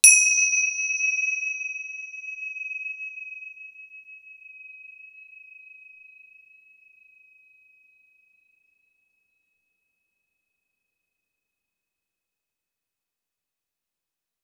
KFwn7D0qqmD_Son-étoile-filante.wav